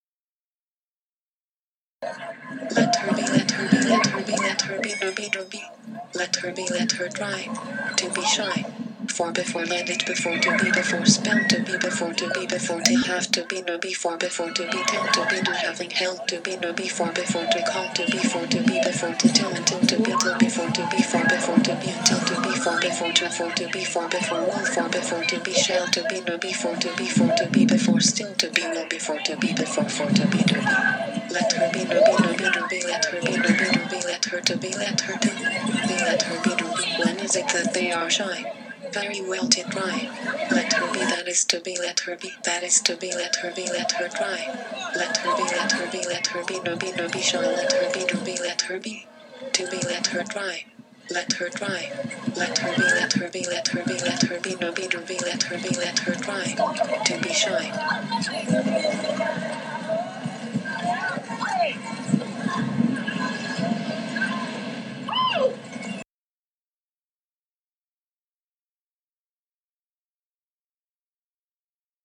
It forms part of a large digital opera on Stein.